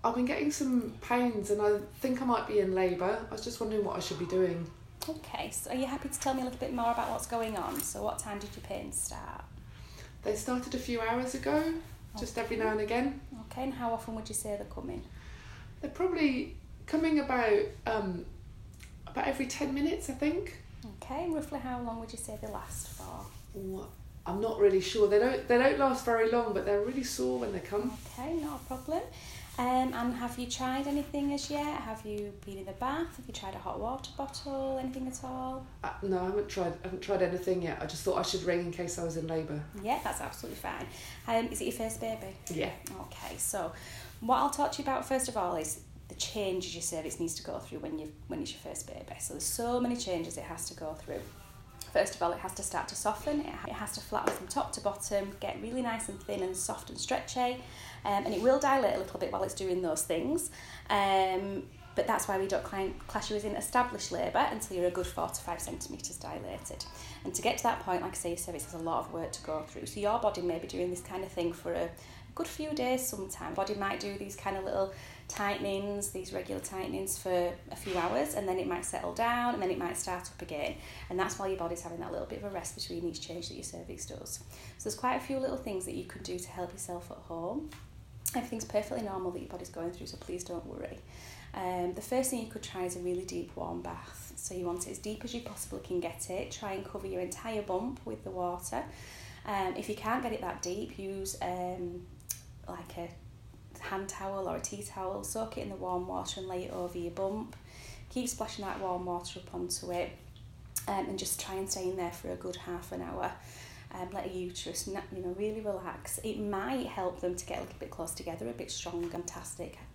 recording of a conversation about early labour with one of our Midwives